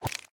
Minecraft Version Minecraft Version latest Latest Release | Latest Snapshot latest / assets / minecraft / sounds / entity / bobber / retrieve1.ogg Compare With Compare With Latest Release | Latest Snapshot
retrieve1.ogg